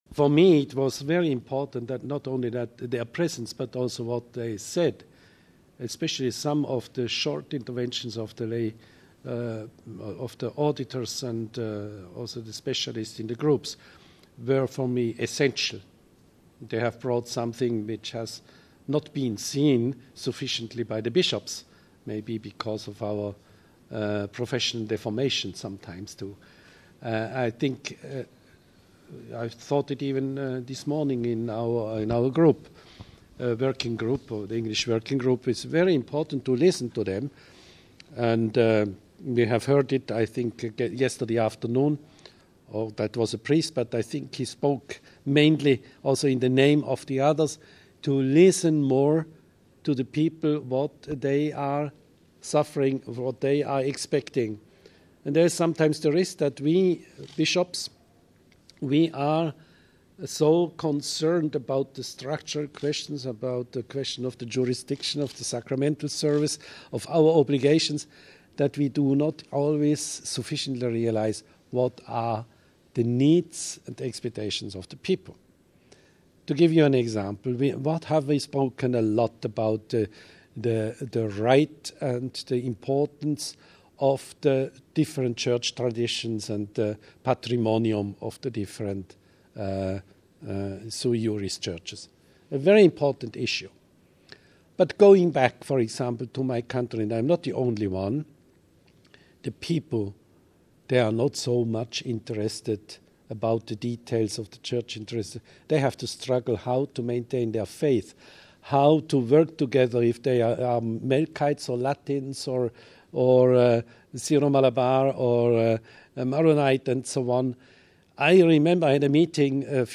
Speaking at a Vatican press briefing Tuesday, the Apostolic Vicar in the Arabian Peninsula, Bishop Paul Hinder spoke of the importance he gives to the voice of women and laity in a region where churches are struggling to meet the needs of vast numbers of incoming Christian migrant workers from Asia and Africa.
Bishop Hinder spoke of the importance of hearing the voices of women and laity at the Synod: